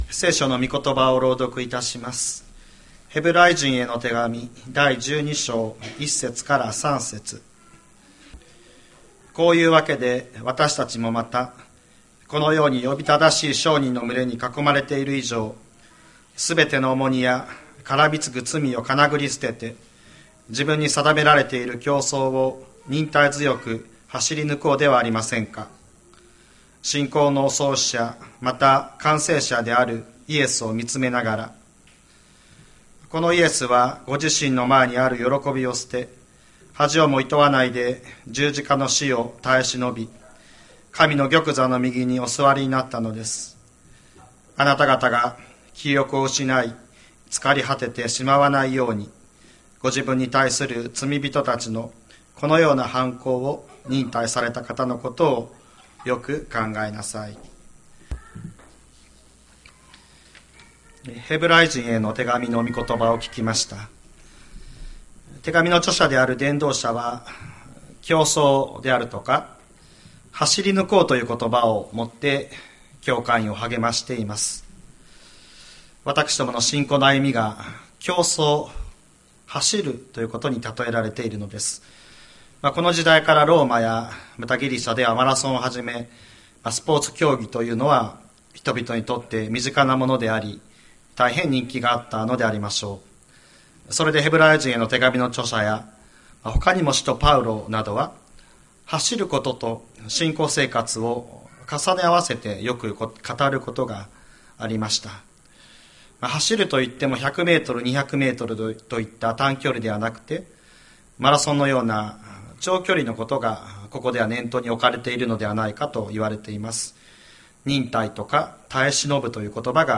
2023年07月02日朝の礼拝「走り抜こう」吹田市千里山のキリスト教会
千里山教会 2023年07月02日の礼拝メッセージ。